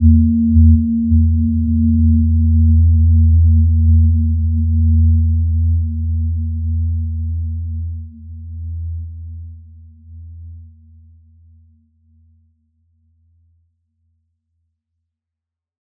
Gentle-Metallic-4-E2-p.wav